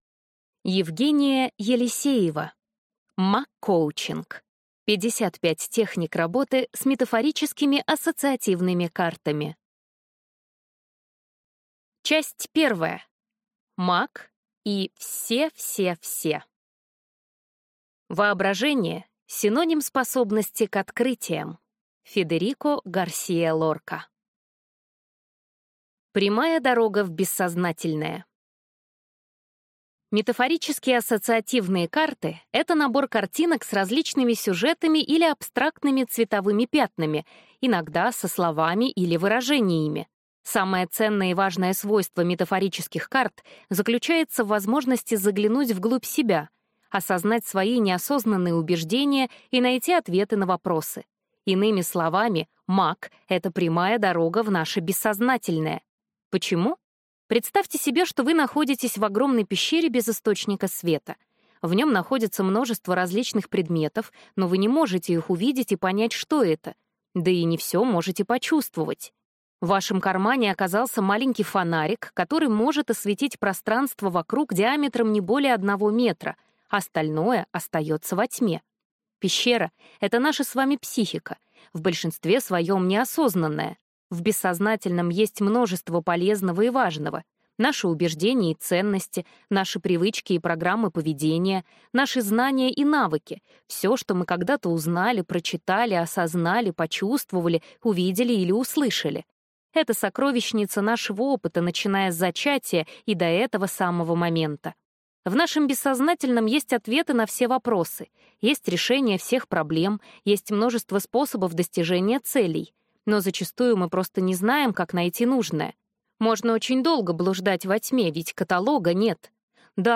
Аудиокнига МАКоучинг. 55 техник работы с метафорическими ассоциативными картами | Библиотека аудиокниг